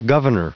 Prononciation du mot governor en anglais (fichier audio)
Prononciation du mot : governor